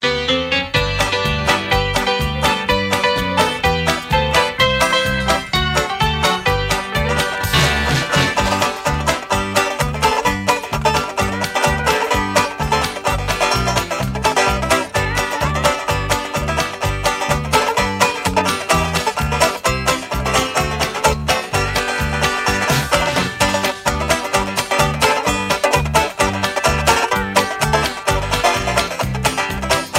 Singing Call (instrumental) + Patter